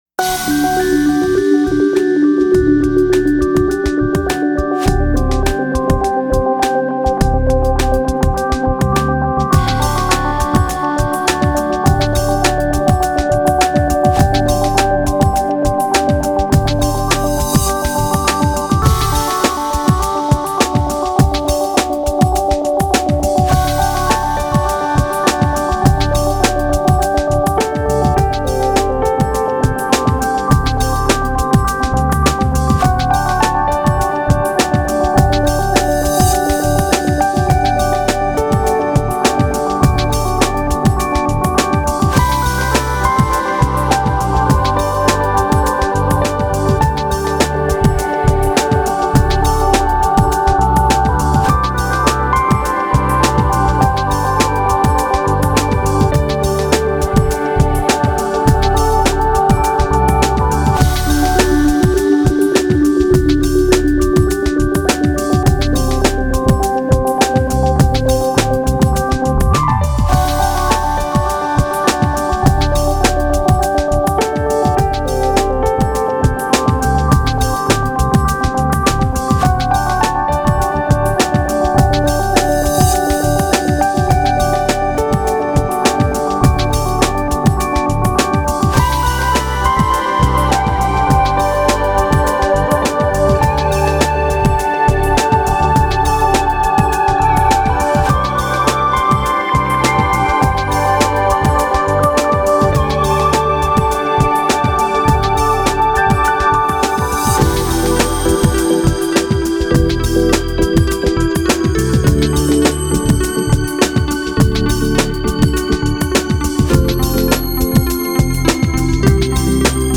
New age Нью эйдж Музыка new age